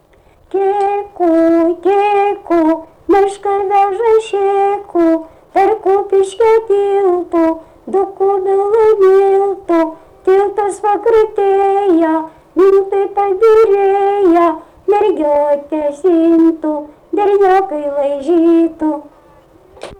smulkieji žanrai
vokalinis